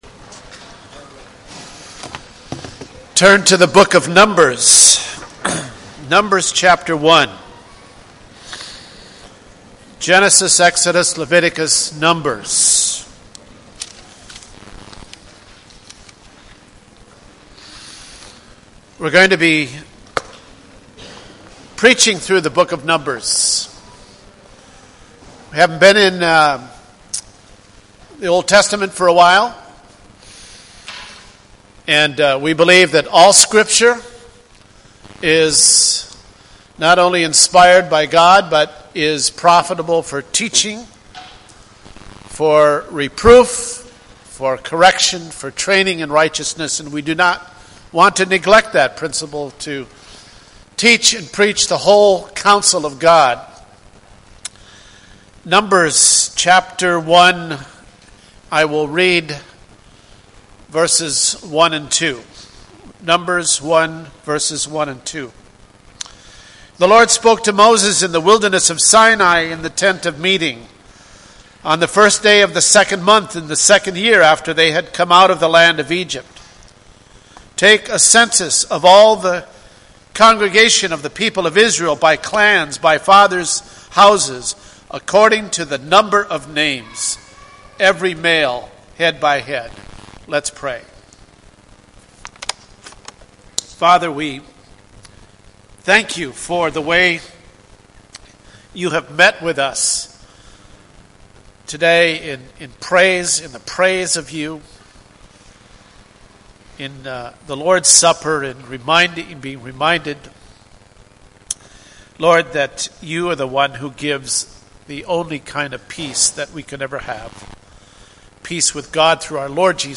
Numbers 1:1-2 Service Type: Sunday Morning %todo_render% « Hallelujah!